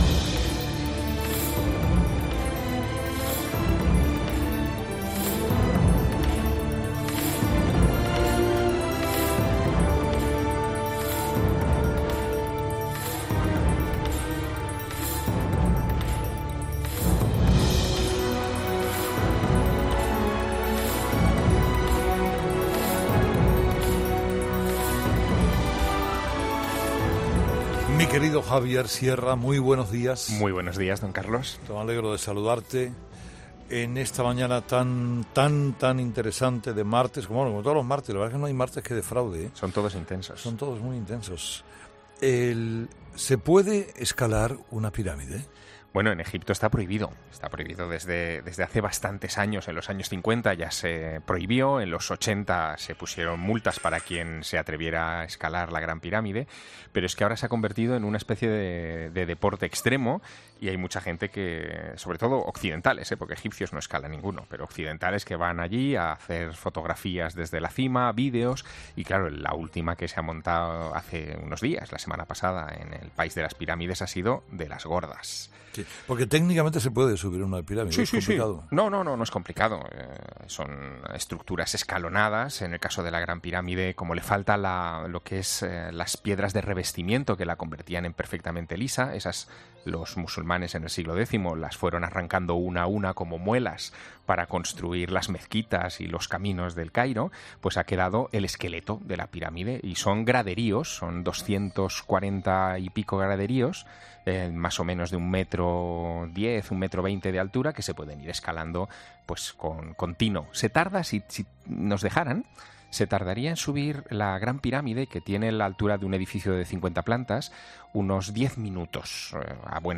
Una semana más, Javier responde a las preguntas formuladas por Carlos Herrera, ¿por qué lo sucedido en la Gran Pirámide ha levantado tanta polvareda?, ¿existen leyes que penan esta clase de incursiones en Egipto?